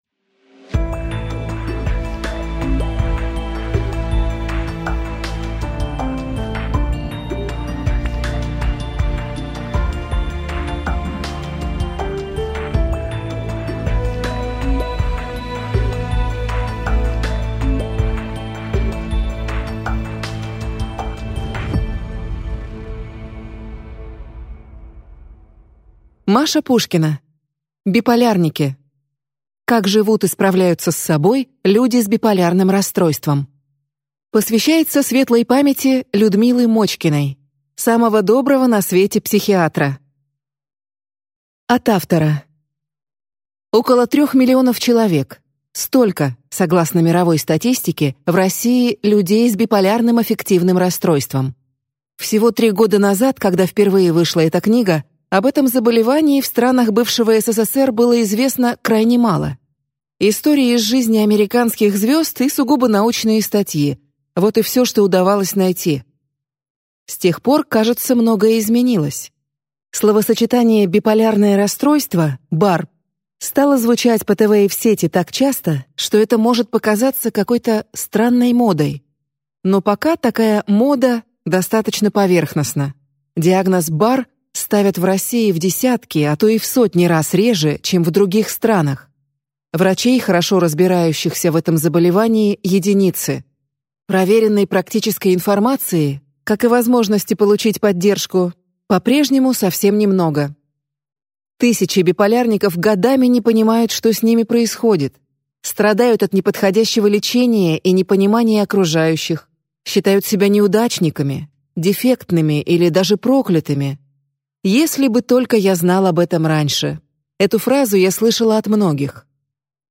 Аудиокнига Биполярники: как живут и справляются с собой люди с биполярным расстройством | Библиотека аудиокниг